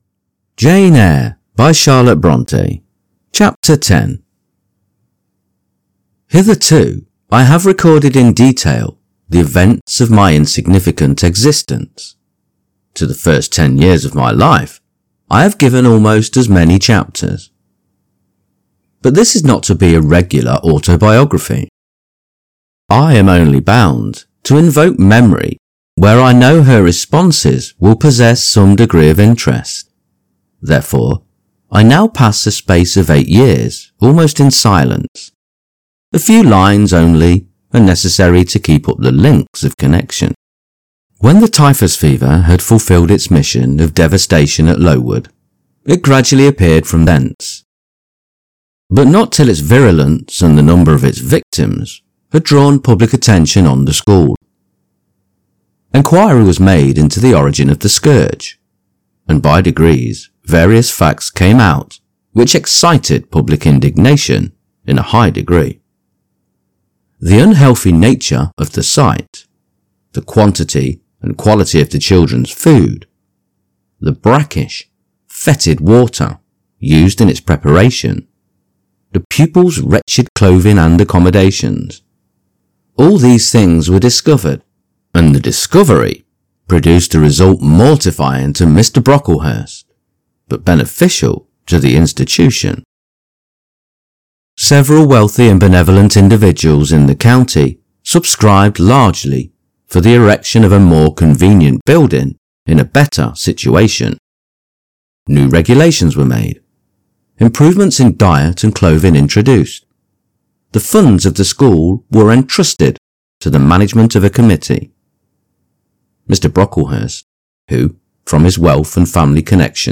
Jane Eyre – Charlotte Bronte – Chapter 10 | Narrated in English